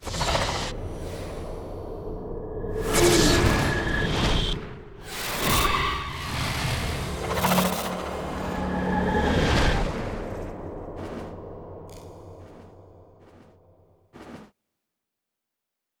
FA Marathon_Starting Next Monday15_ST SFX.wav